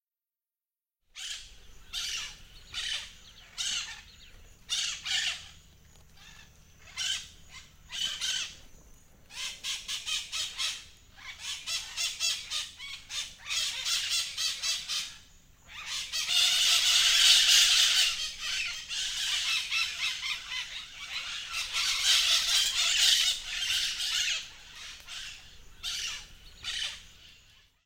parrot-sound